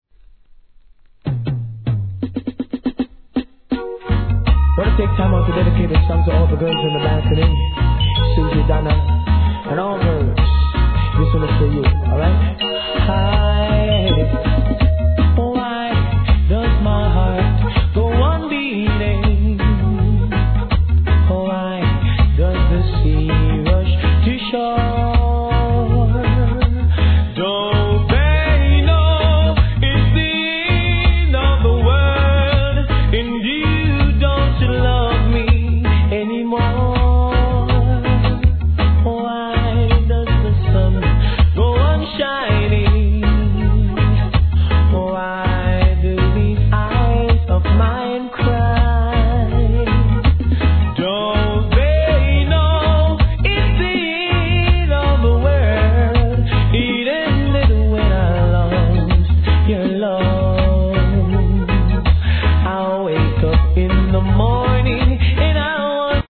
REGGAE
優しくSWEETに歌い上げる作品群!